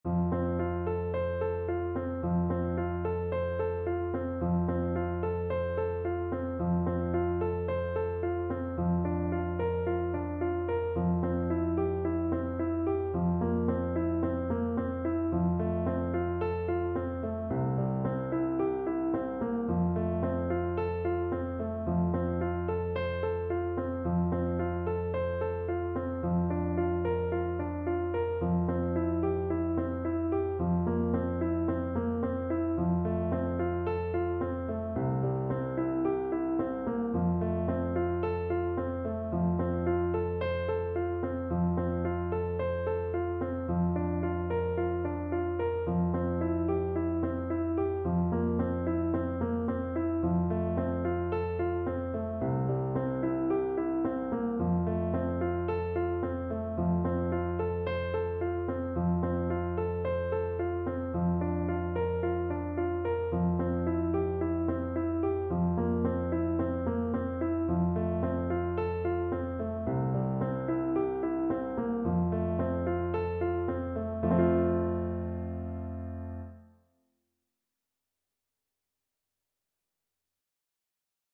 French Horn
Allegro Moderato =c.110 (View more music marked Allegro)
4/4 (View more 4/4 Music)
F major (Sounding Pitch) C major (French Horn in F) (View more F major Music for French Horn )
Traditional (View more Traditional French Horn Music)